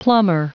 Prononciation du mot plumber en anglais (fichier audio)
Prononciation du mot : plumber